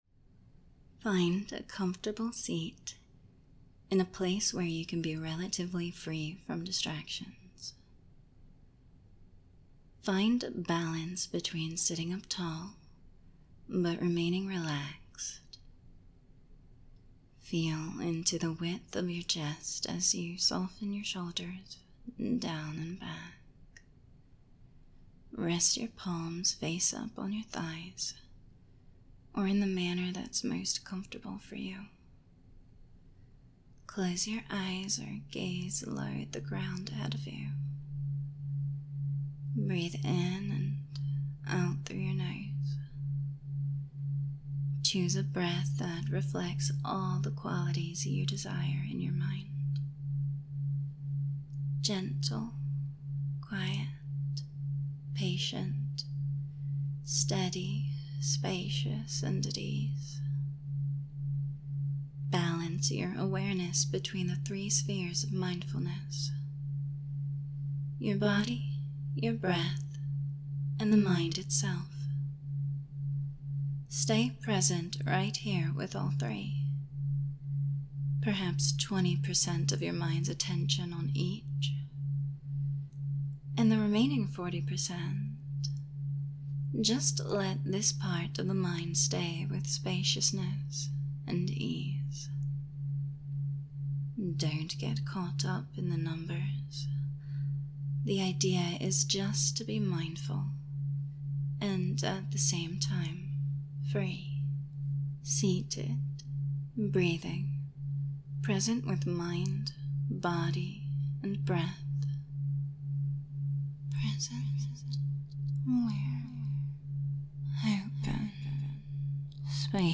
F4A
Affectionate, Soft Spoken
Layers, Echoes
Binaurals